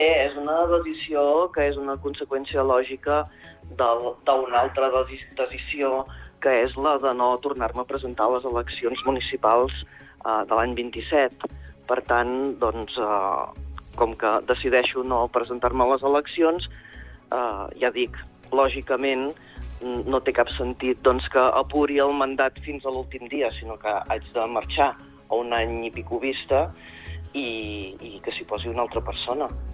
Entrevistes SupermatíSupermatí